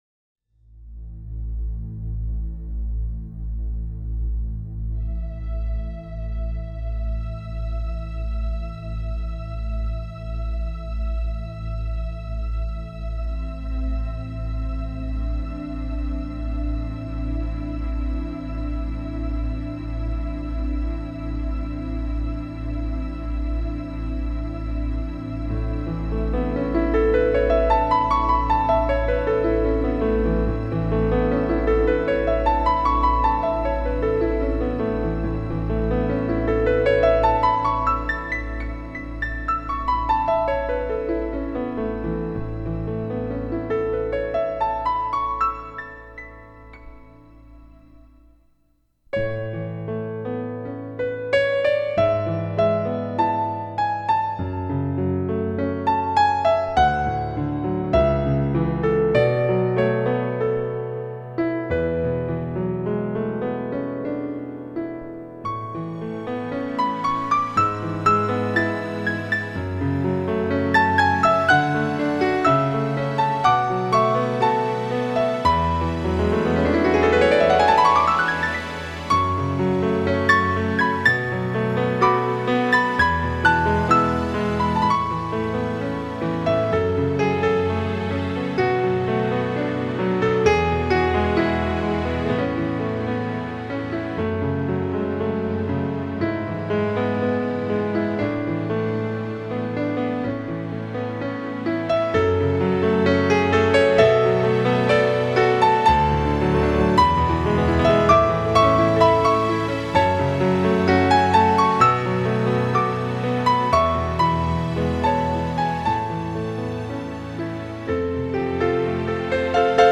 新音乐